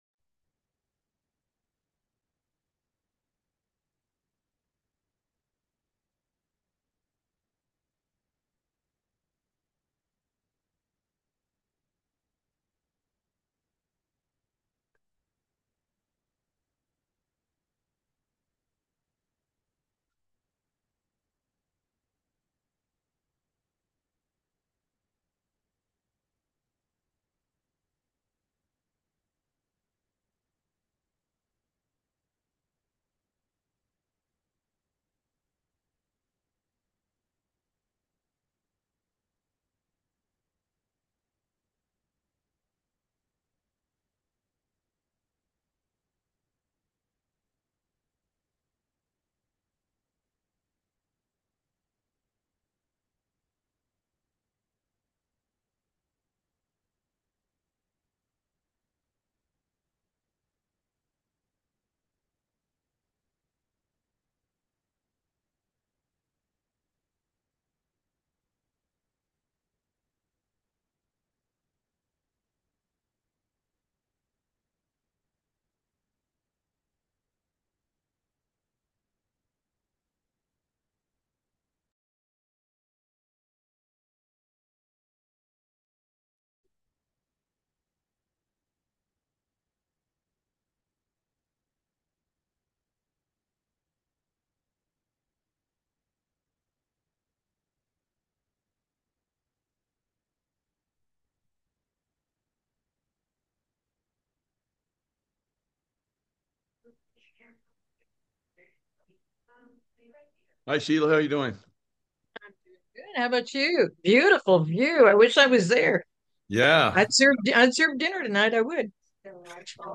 In a lively chat, two friends explore their lives, tech experiences, and current events. They dive into a book about saving America, discuss political happenings, and share thoughts on history and justice. With tales of secret societies and global politics, they highlight the importance of unity, truth, and public involvement for a brighter future.